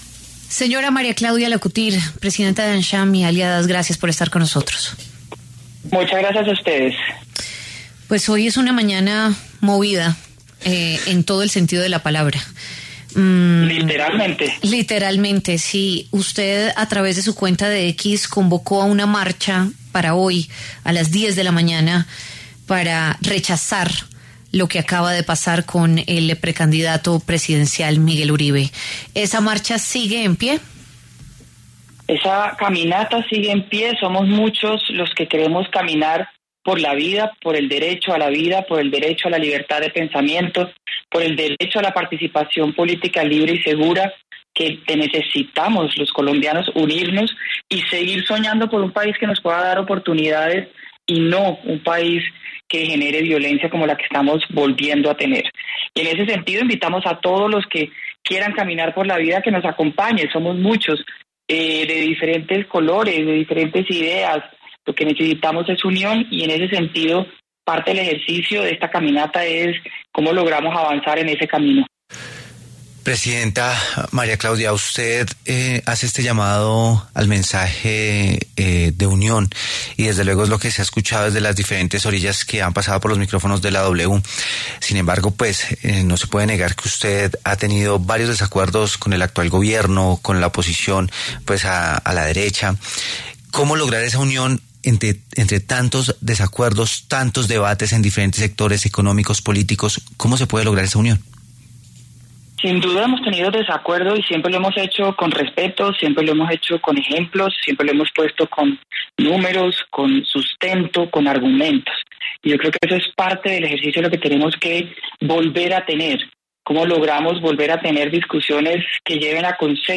En diálogo con W Fin De Semana, la directora de AmCham, María Claudia Lacouture, se refirió al atentado contra Miguel Uribe Turbay, precandidato presidencial del Centro Democrático, y por el que convocó a una marcha pacífica.